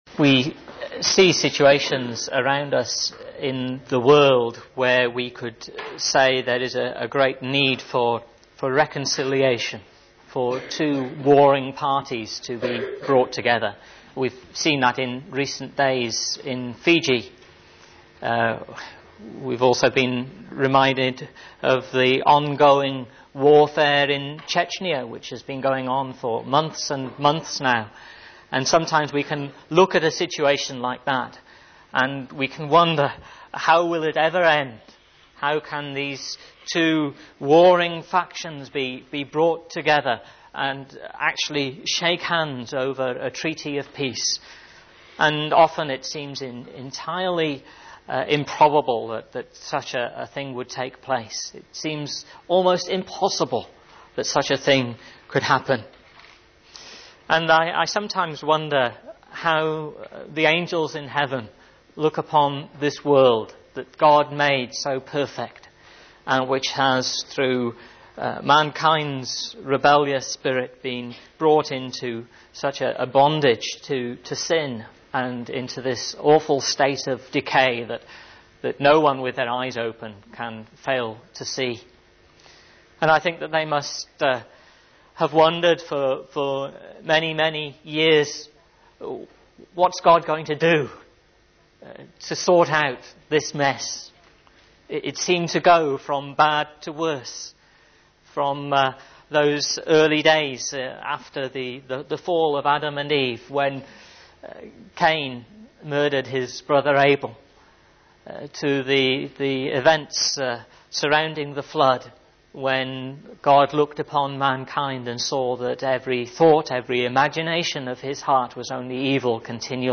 GBC Sermon Archive